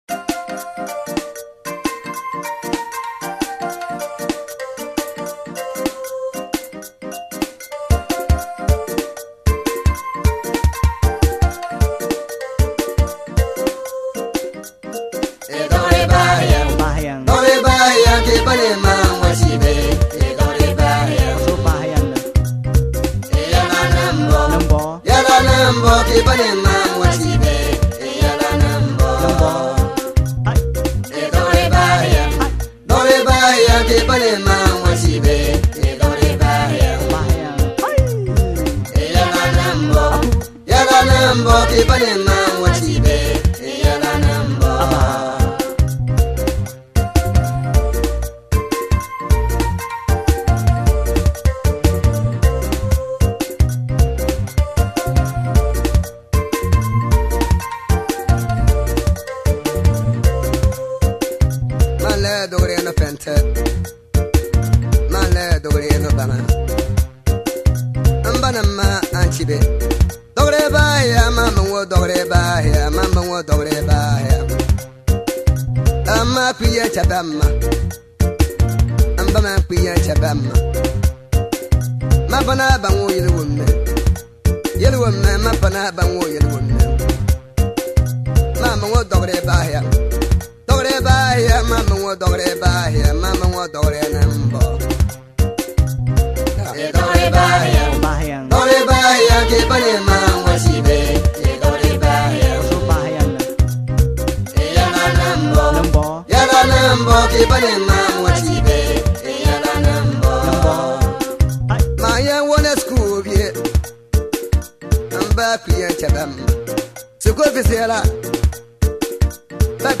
Highlife